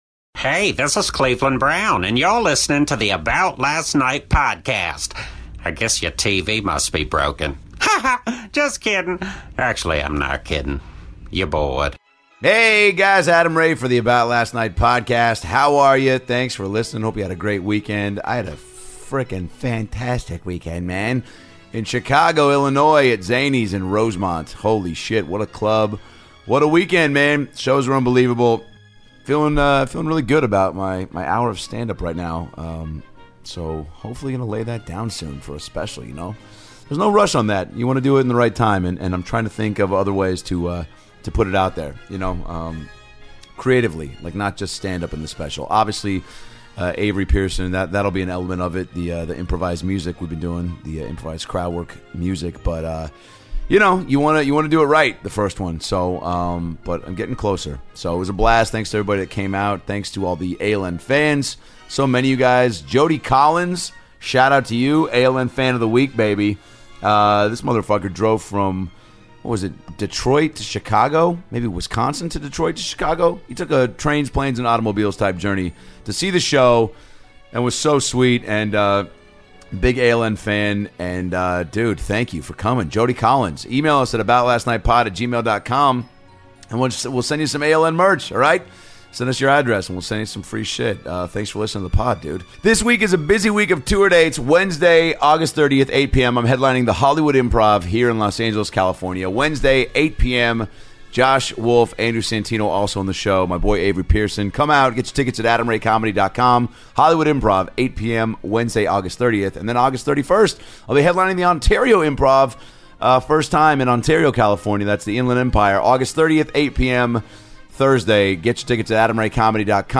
Actor and 90's heartthrob Andrew Keegan drops by to the podcast to talk about his acting career and shares a tons great behind the scenes stories, what it was like working with people such as Heath Ledger, how he likes living in Venice, his work with "Full Circle Venice", his ...